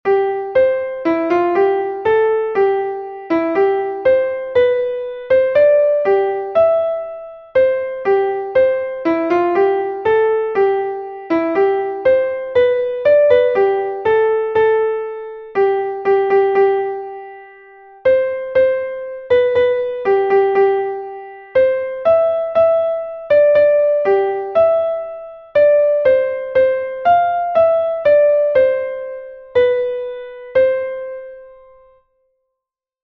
Einstimmige Melodie im Violinschlüssel, C-Dur, 4/4-Takt, mit der 1. Strophe des Liedtextes.
wer-recht-in-freuden-wandern-will_klavier_melodiemeister.mp3